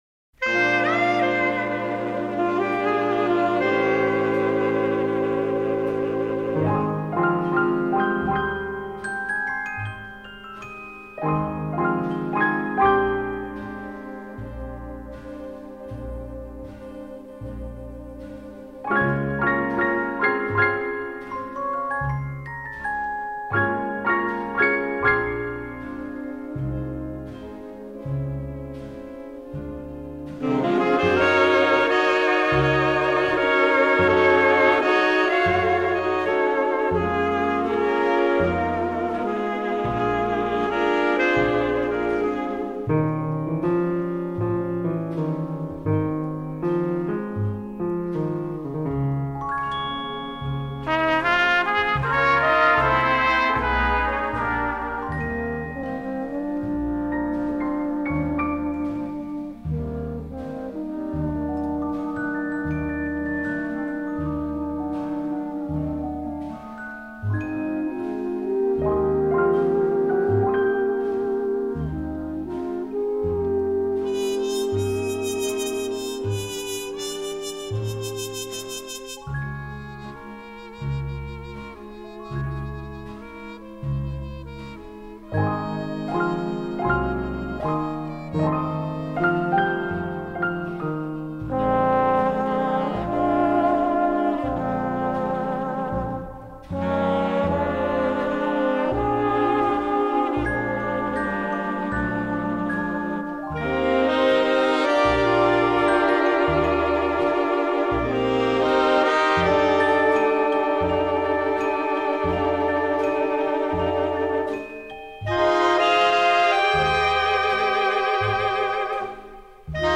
А так как мне почти всегда нравится своеобразное триединство ( клипповость)) - изображение (в данном случае -фото) + соответствующая музыка +  слова (найти самые подходящие слова - чуть ли не самое сложное)),  то и появилась ещё  и музыка - между спокойной оркестровой и джазом - предпочтение отдано джазу - а комментарии показались  ненужными.))